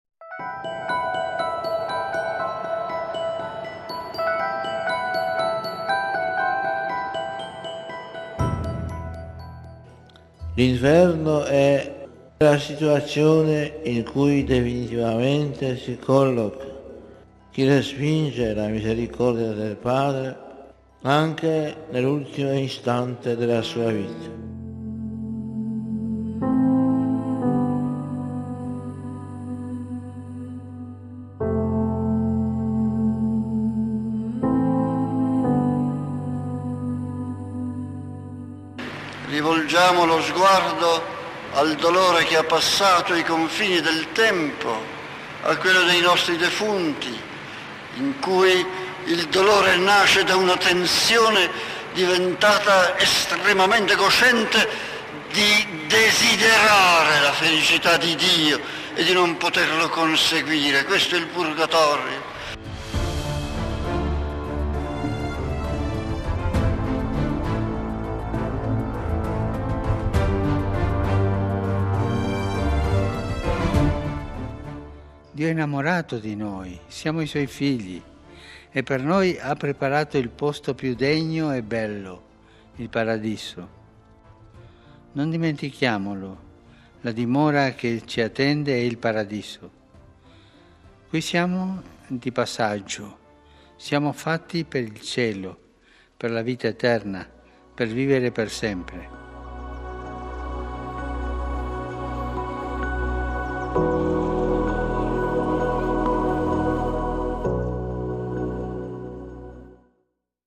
Attingendo all’archivio sonoro della Radio Vaticana ripercorriamo alcune riflessioni dei Pontefici sui tre possibili destini per l’anima